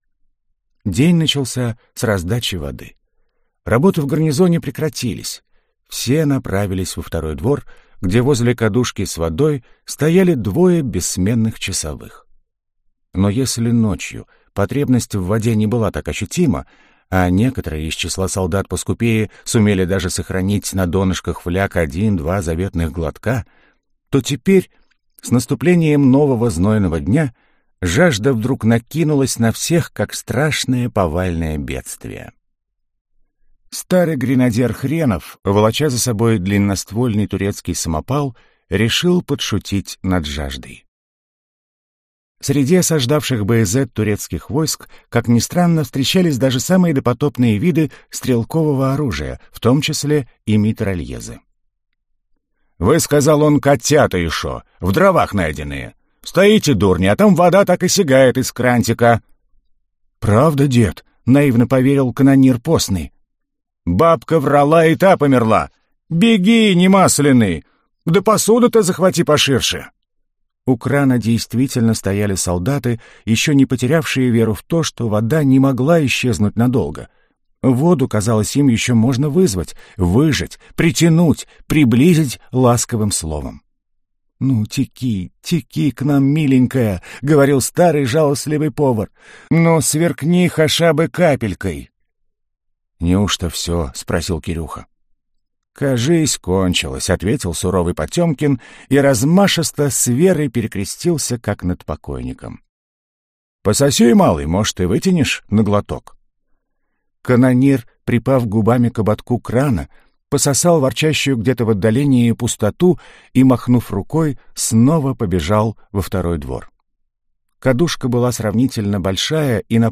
Аудиокнига Баязет (часть вторая) | Библиотека аудиокниг